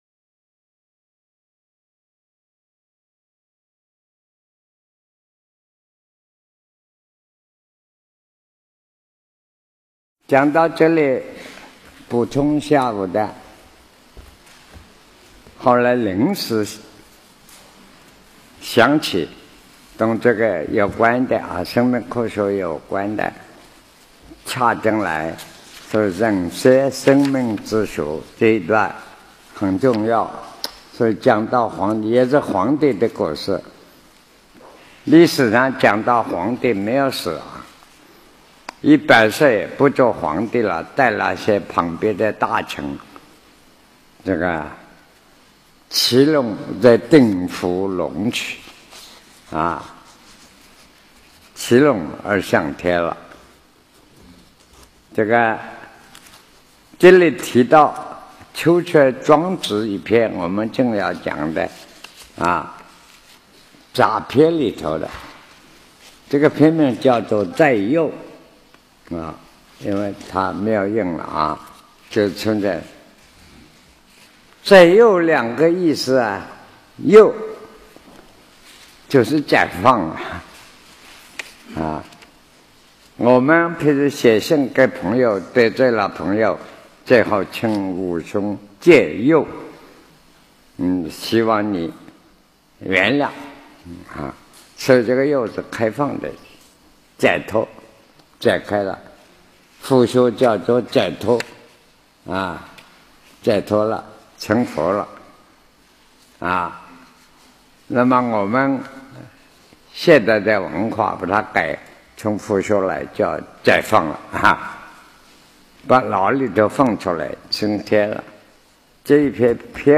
南师讲《黄帝内经》06